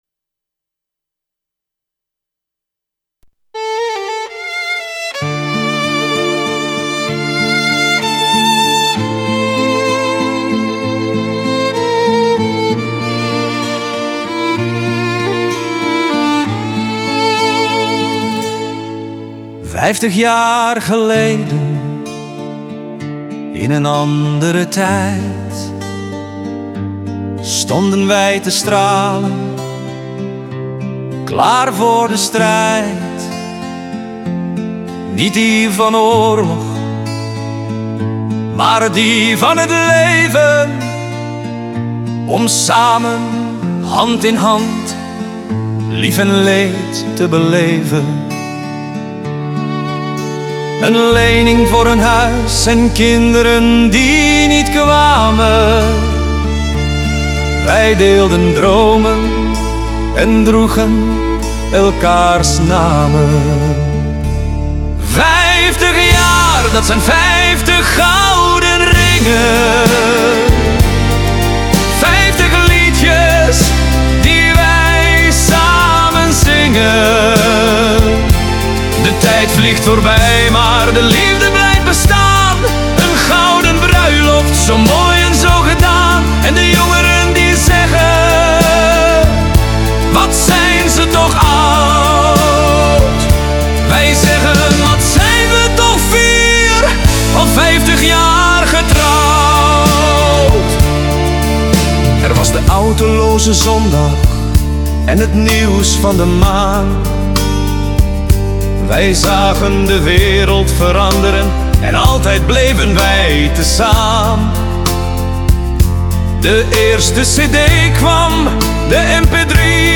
Instrumentale versie / karaoke